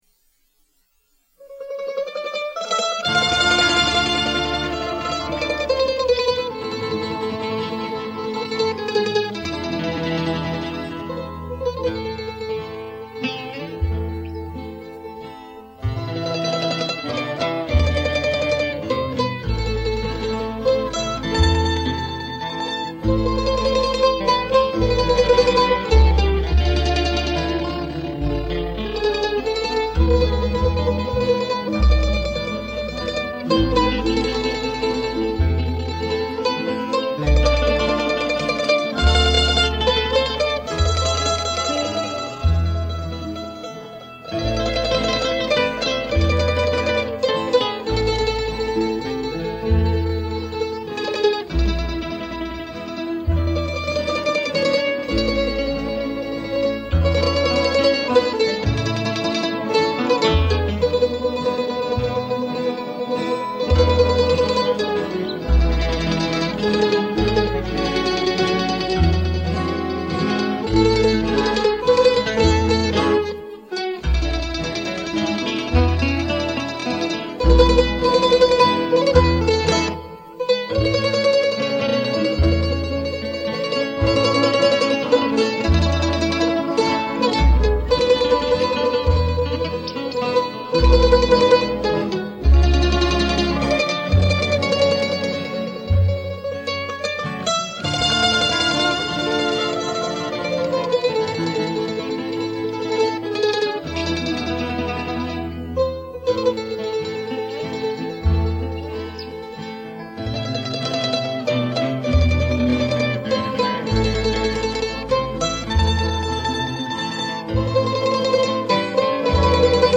mandocello